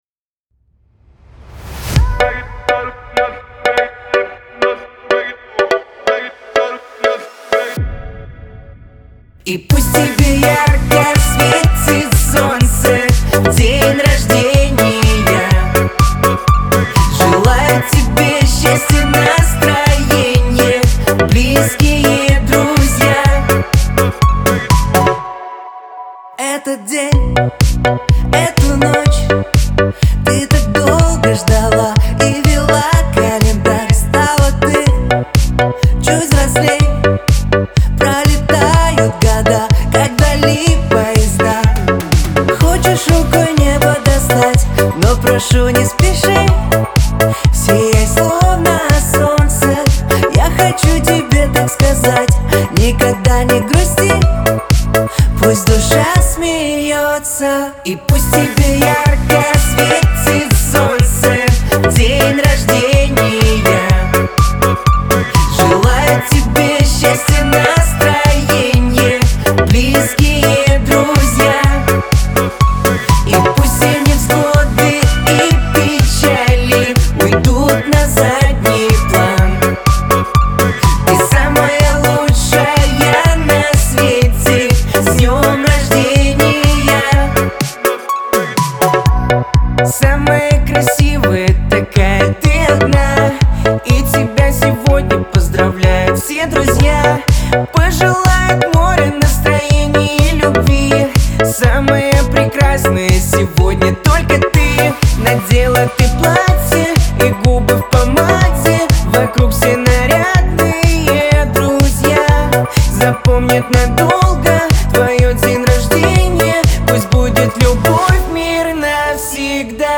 дуэт , ансамбль , pop , dance
эстрада
диско